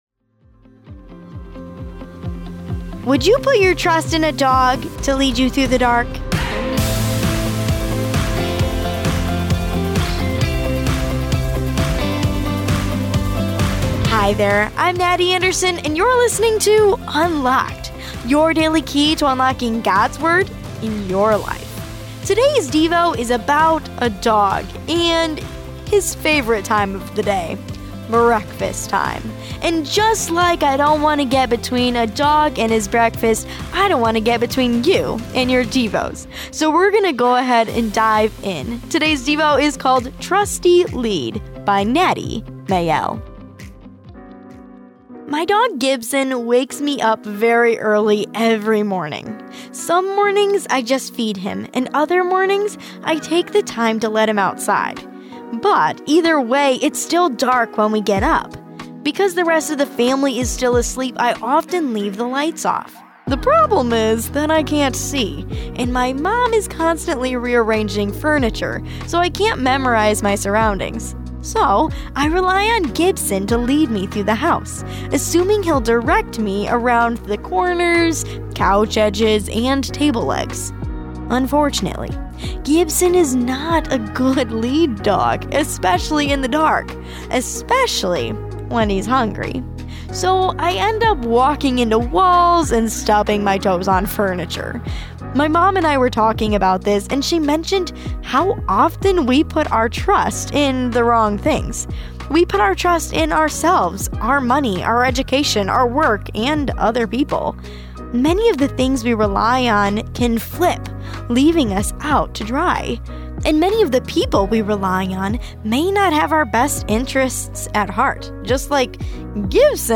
Unlocked is a daily teen devotional, centered on God’s Word. Each day’s devotion—whether fiction, poetry, or essay—asks the question: How does Jesus and what He did affect today’s topic?